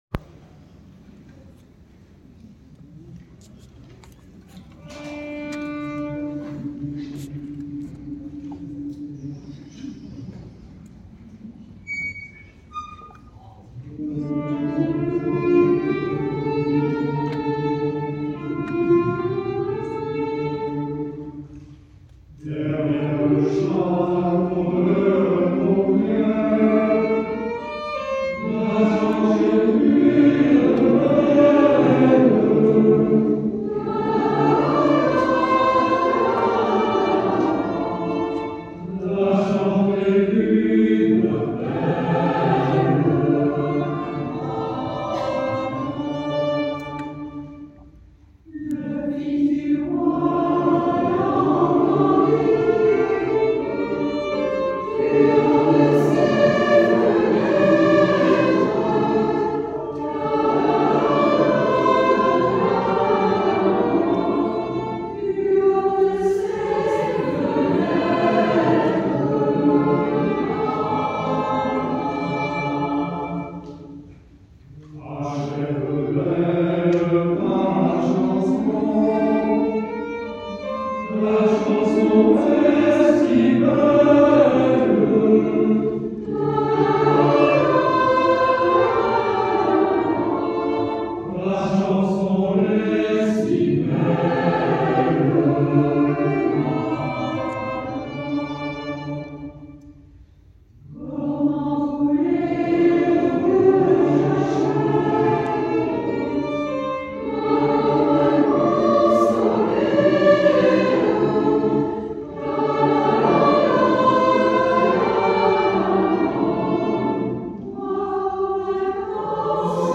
Dimanche 15 Mars à 16h en l'église de MEYSSAC:
Les 2 choeurs réunis pour le bis : Derrière le château de Montvieil